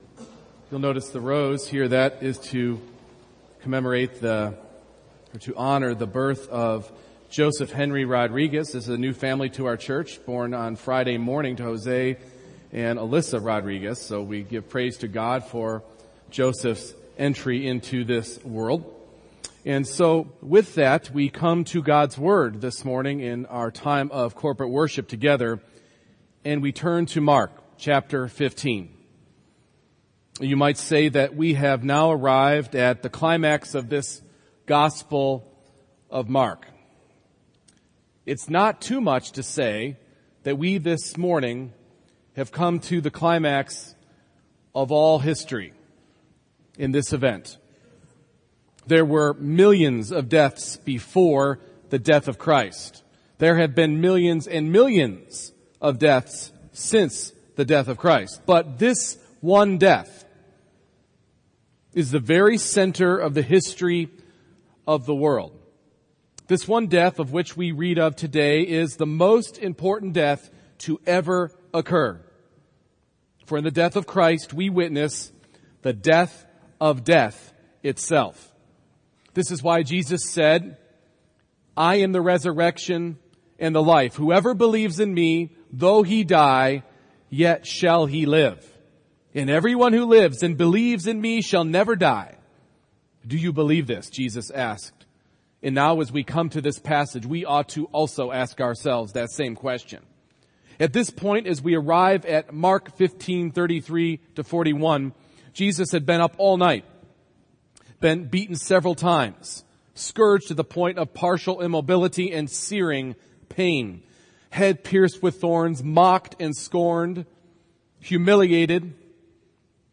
Mark 15:33-41 Service Type: Morning Worship Salvation comes to us from Christ’s finished work on the Cross.